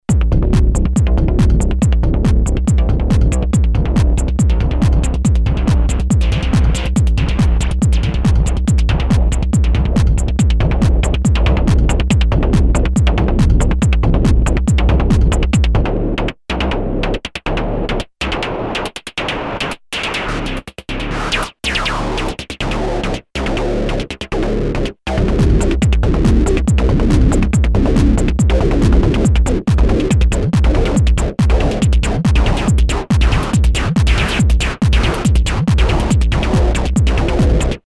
Mostly DJ-Live oriented in the LOOP FACTORY series grooveboxes based on AN analog physical modeling synthesis and sampled percussions.
click bass
demo filter